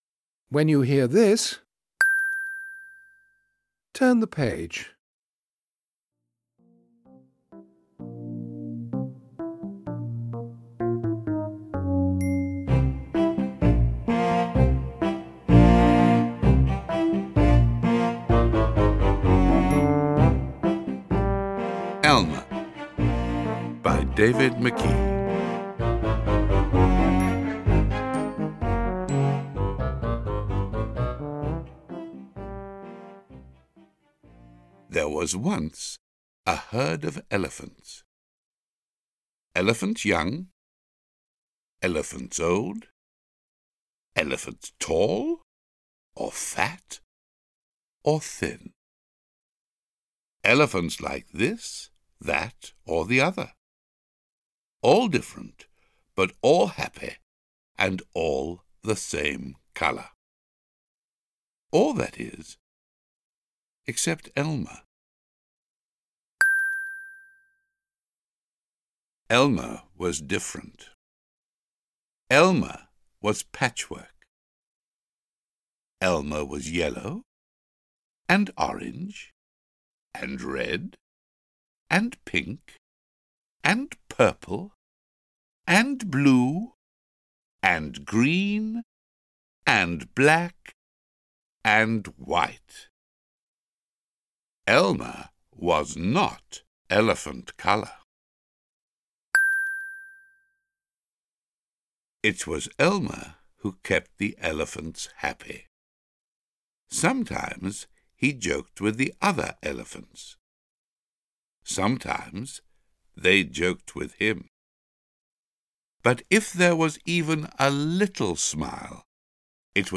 Elmer-Read-By-Joss-Ackland-with-pings.mp3